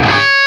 LEAD C#4 LP.wav